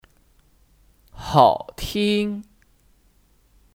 好听 (Hǎotīng 好听)